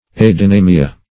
adynamia \ad`y*na"mi*a\ ([a^]d`[i^]*n[=a]"m[i^]*[.a]), n. [NL.